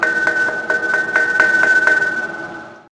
金属制品
标签： 摩擦 金属 工业
声道立体声